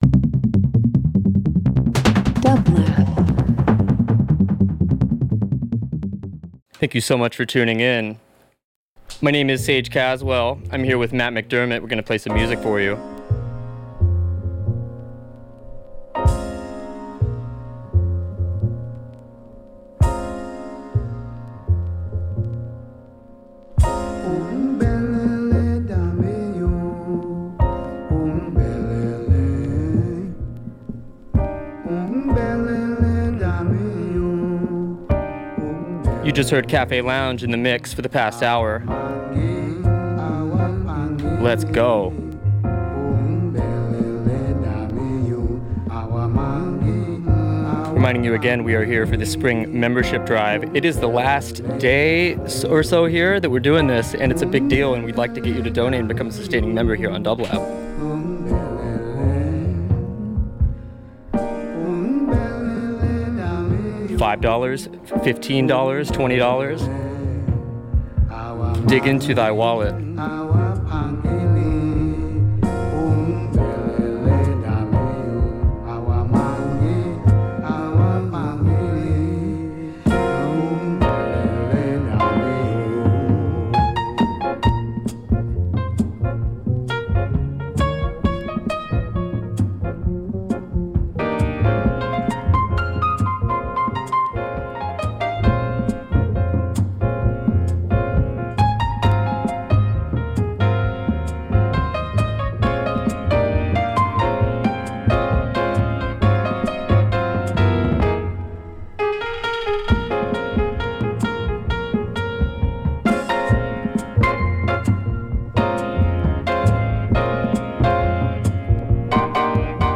Ambient Dance Electronic Folk International